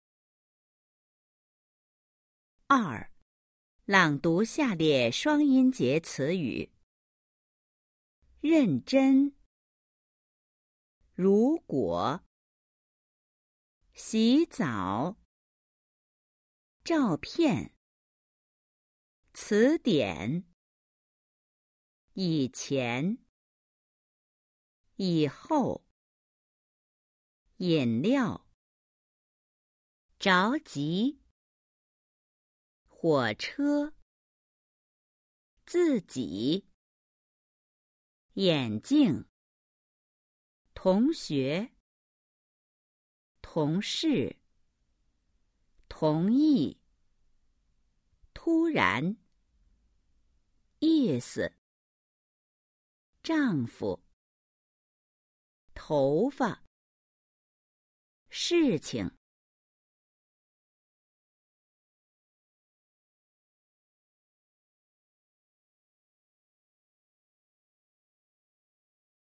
二、朗读下列双音节词语　💿 02-2
Đọc to các từ có hai âm tiết dưới đây.